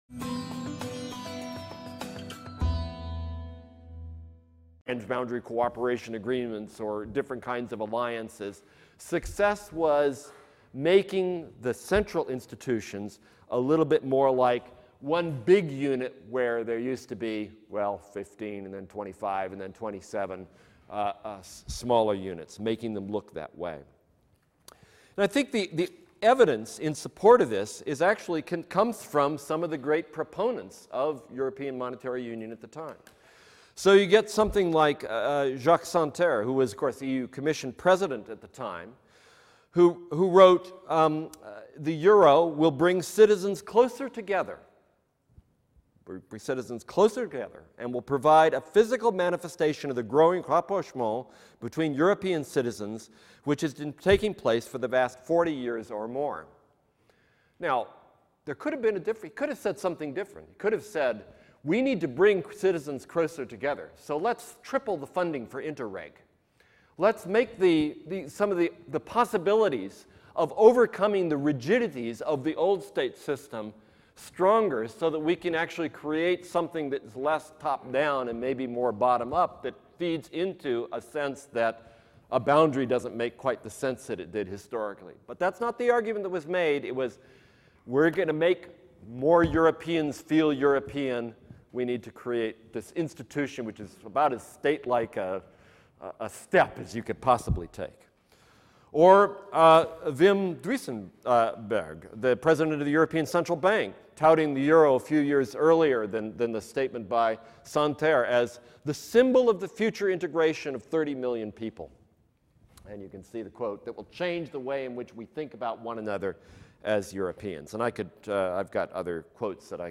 studiumdigitale eLectureportal — Political Geography and Critical Geopolitics Preconference 2012
Keynote Saturday: Geopolitics at the margins